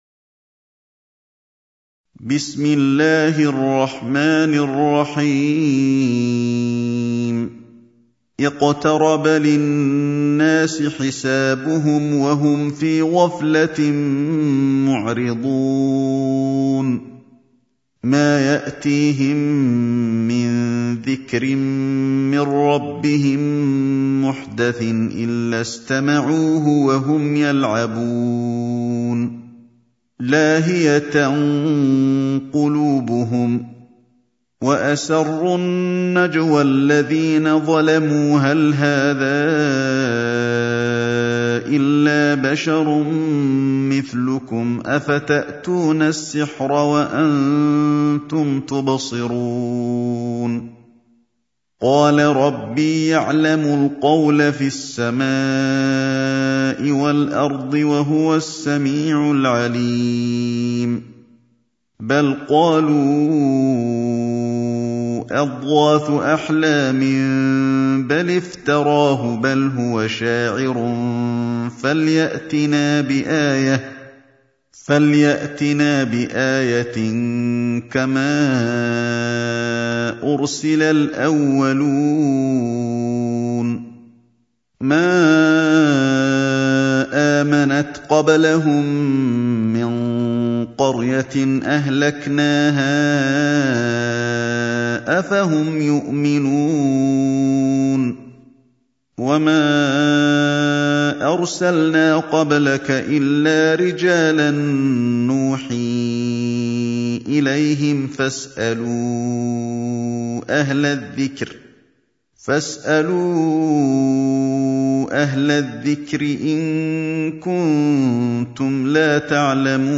سورة الأنبياء | القارئ علي الحذيفي